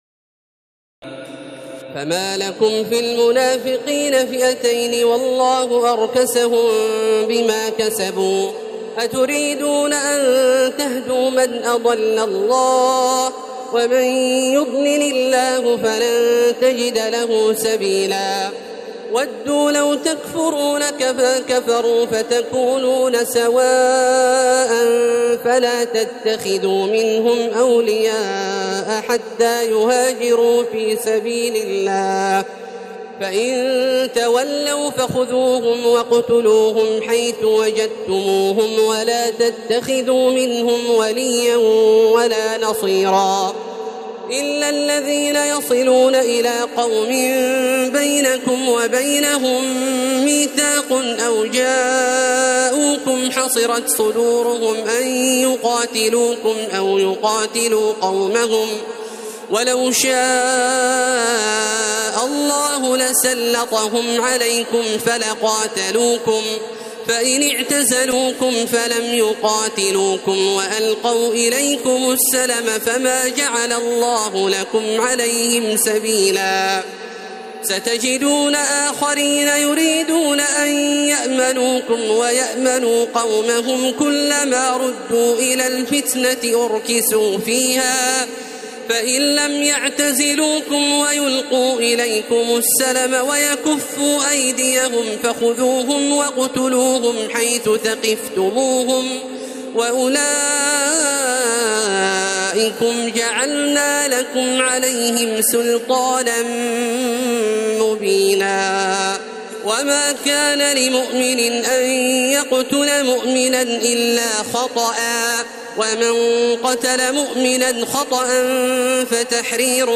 تراويح الليلة الخامسة رمضان 1432هـ من سورة النساء (88-159) Taraweeh 5 st night Ramadan 1432H from Surah An-Nisaa > تراويح الحرم المكي عام 1432 🕋 > التراويح - تلاوات الحرمين